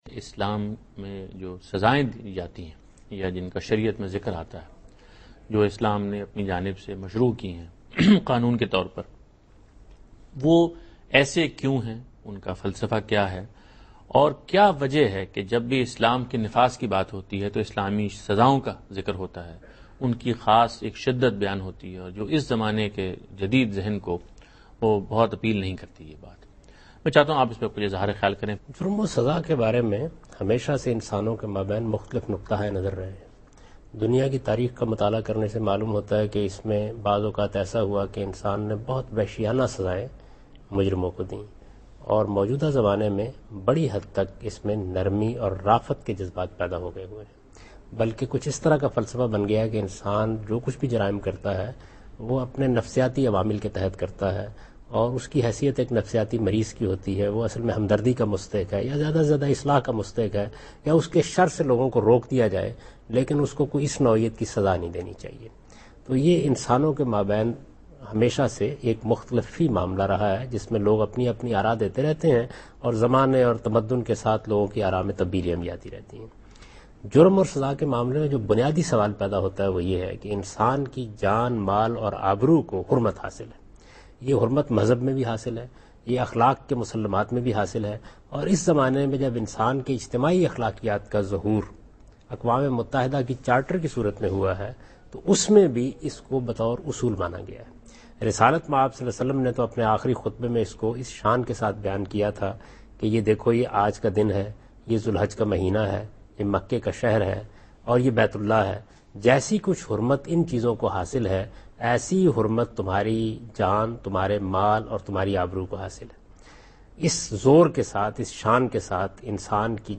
Category: TV Programs / Dunya News / Deen-o-Daanish /
Javed Ahmad Ghamidi answers a question about "Reason behind Islamic Punishments" in program Deen o Daanish on Dunya News.
جاوید احمدا غامدی دنیا نیوز کے پروگرام دین و دانش میں اسلامی سزاوں کی حکمت سے متعلق ایک سوال کا جواب دے رہے ہیں۔